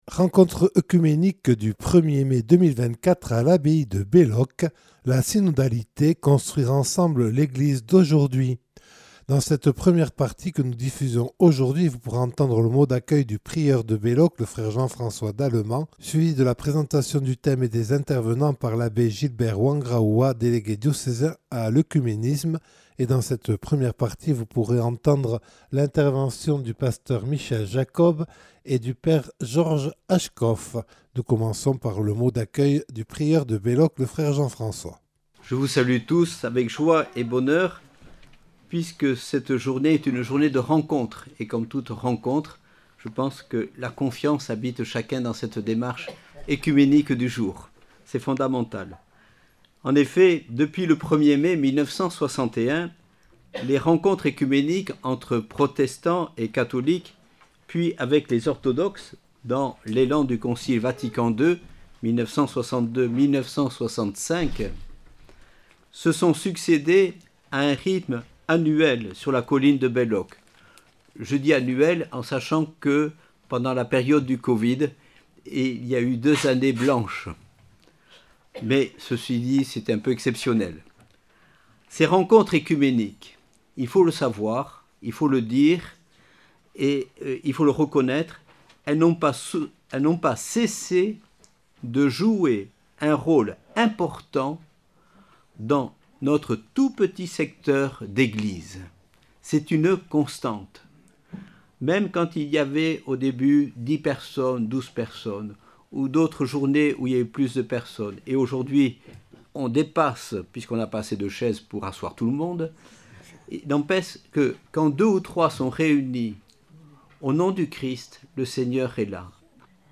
Rencontre oecuménique du 1er mai 2024 à Belloc consacrée à la synodalité vue et vécue par nos Eglises.